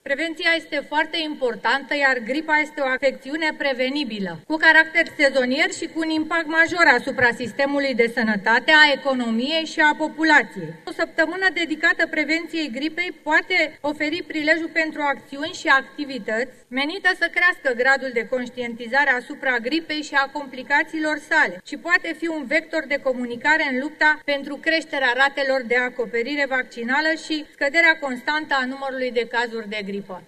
Instituirea unei săptămâni de prevenţie pentru gripă poate creşte gradul de acoperire vaccinală, spune social democrata Cristina Dinu: